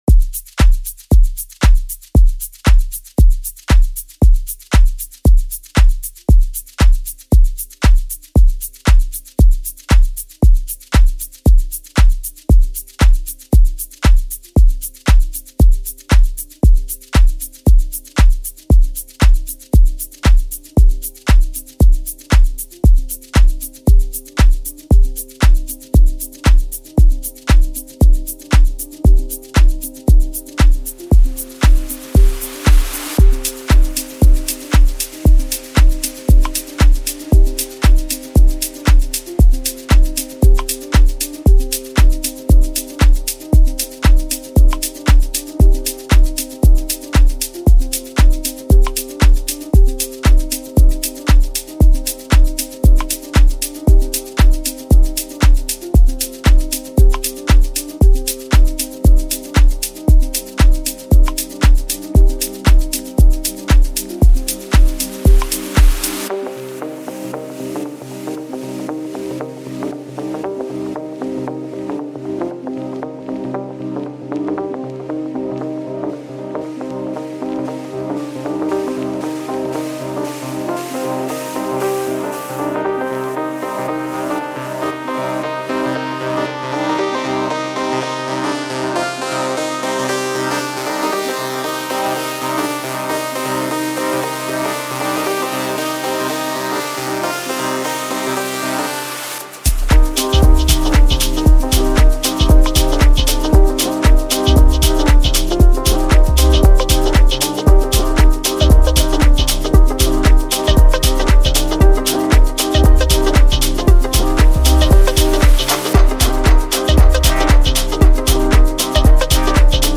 Home » Hip Hop » Amapiano » DJ Mix